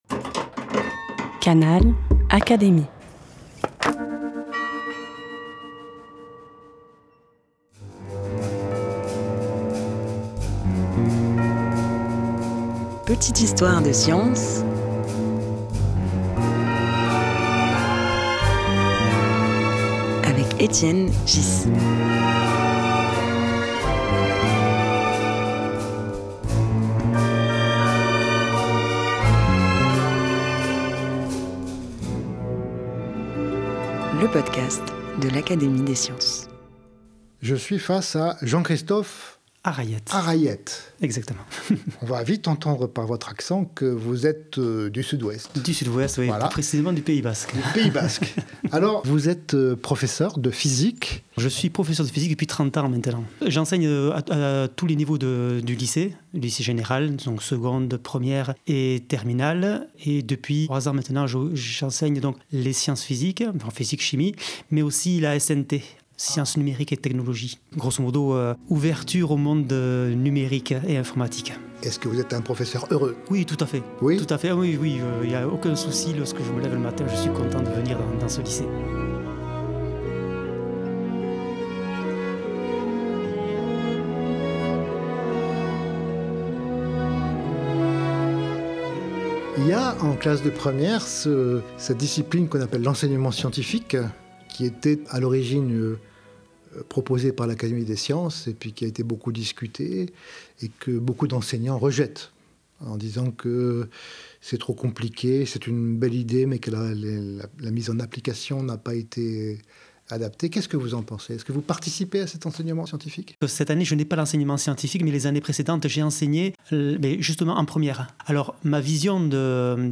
Un podcast animé par Étienne Ghys, proposé par l'Académie des sciences.